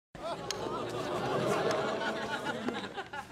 Disney Crowd Sound Effect Download: Instant Soundboard Button
Reactions Soundboard548 views